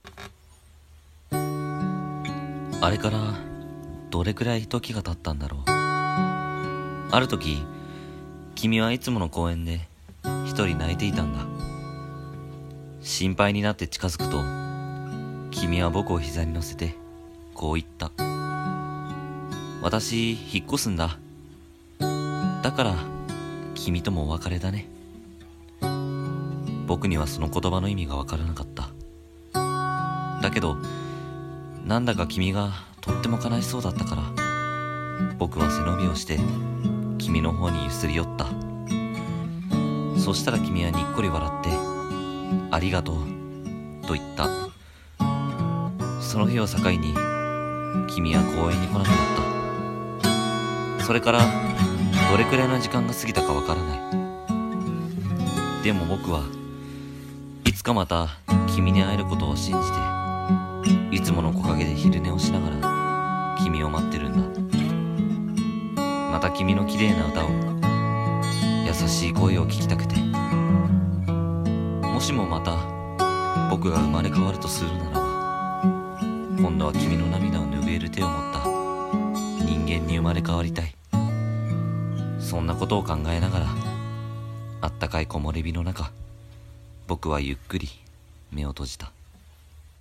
【朗読劇】ボクは猫 後編